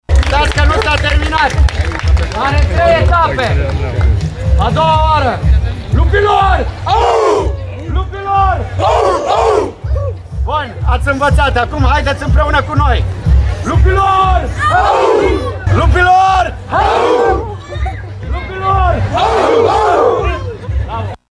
Cu toate acestea, Cetatea medievală din Tg.Mureș a fost plină, iar la intrare Ordinul ”Cavalerii Lup” din Garda de Mureș a prezentat onorul și a defilat în fața mulțimii: